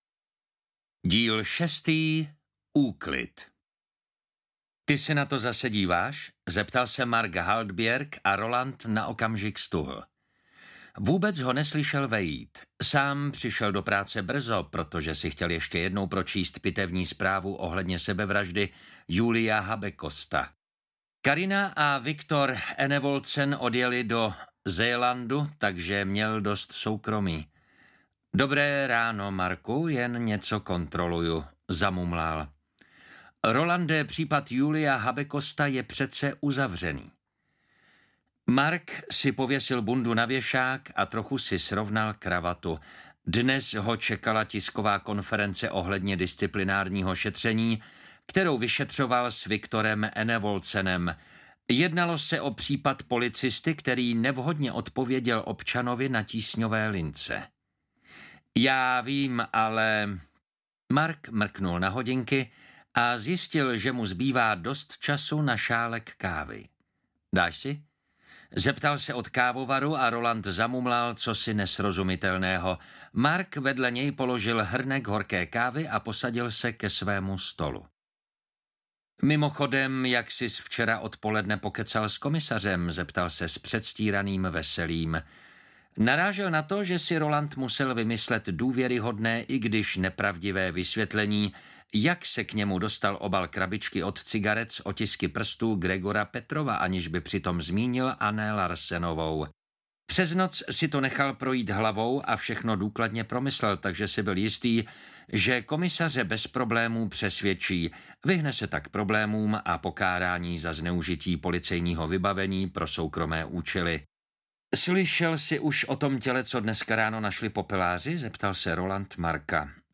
Uklízeč 6: Úklid audiokniha
Ukázka z knihy